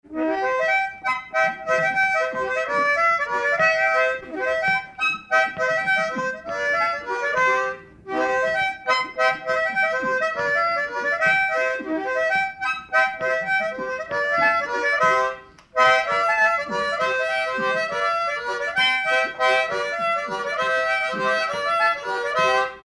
danse : valse
circonstance : bal, dancerie
Pièce musicale inédite